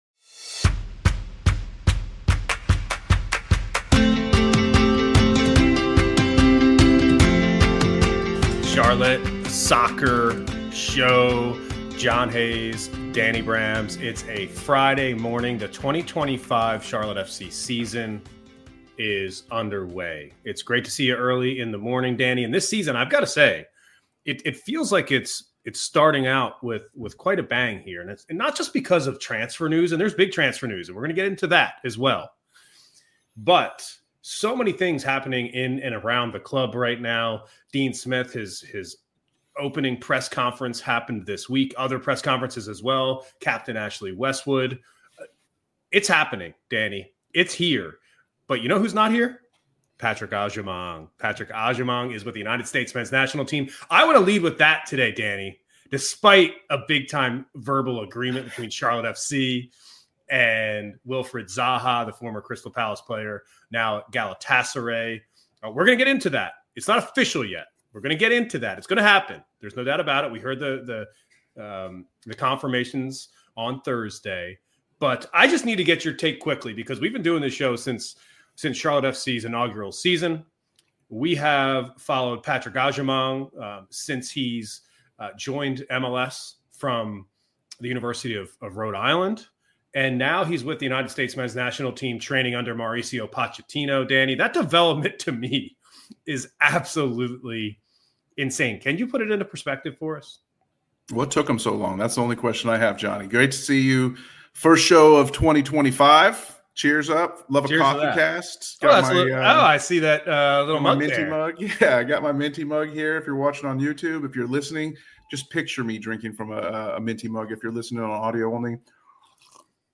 Two supporters and media pros talk Charlotte as a Soccer City.
Together they tackle the Charlotte Soccer scene by telling fan stories & paying tribute to the Carolinas' best brews. Each episode feels like trading tales over pints at the pub, pour yourself a glass and enjoy!